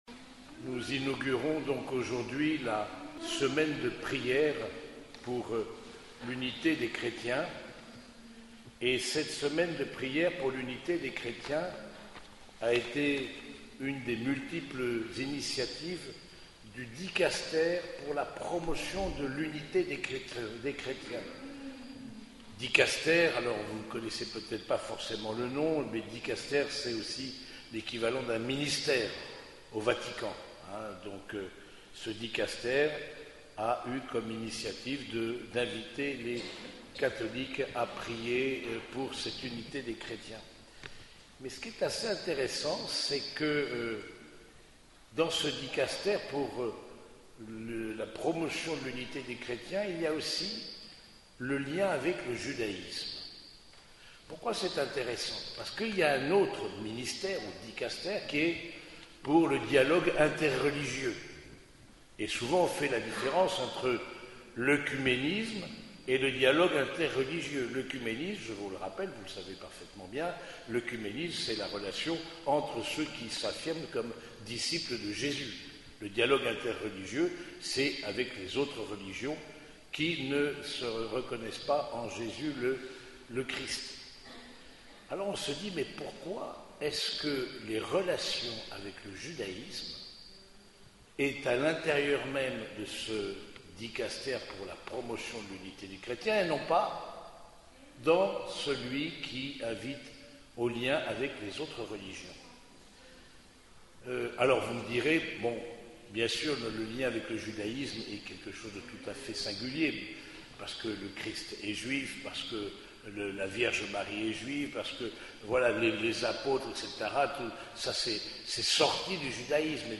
Homélie du 2e dimanche du Temps Ordinaire
Cette homélie a été prononcée au cours de la messe dominicale célébrée à l’église Saint-Germain de Compiègne.